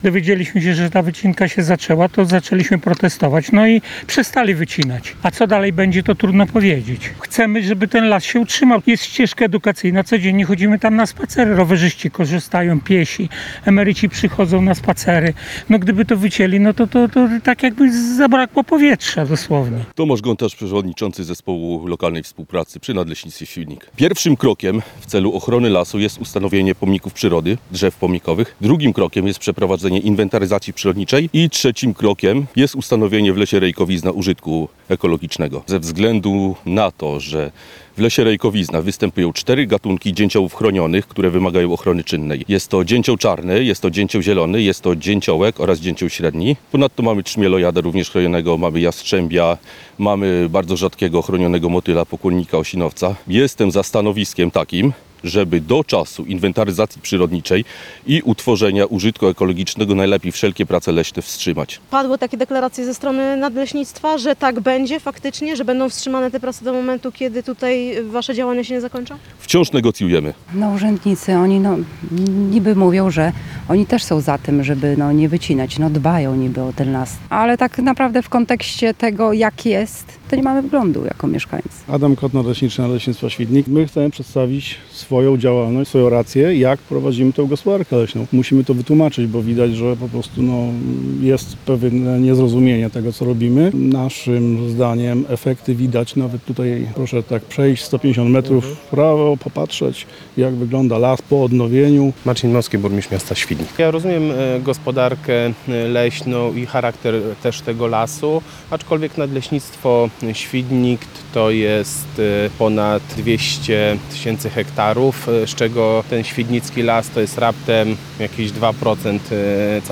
Powiedzieliśmy „sprawdzam” i na miejsce pojechała nasza reporterka. Ustaliła, że Lasy Państwowe nadal deklarują zaprzestanie wycinki do czasu osiągnięcia wspólnego stanowiska z Zespołem Lokalnej Współpracy.